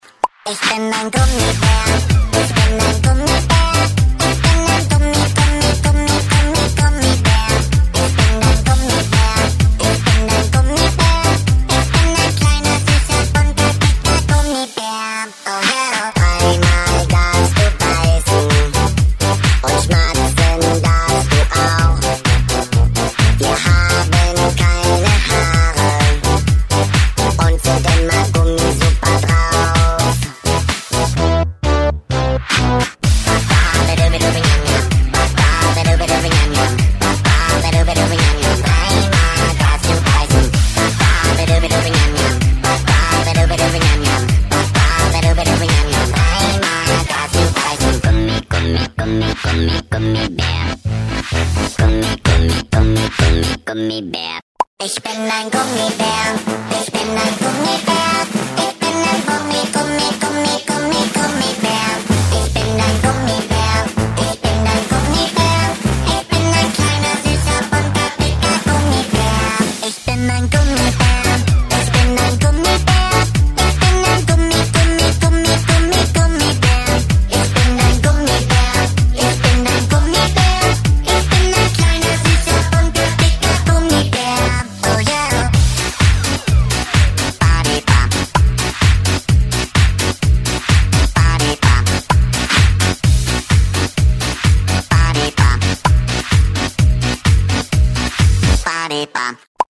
• Качество: 128, Stereo
Старенькая но очень веселая песенка